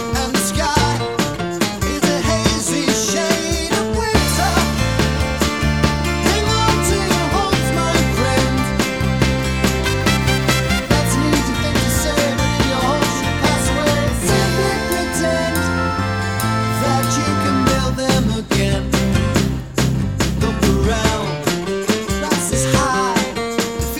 No Harmony Pop (1960s) 2:18 Buy £1.50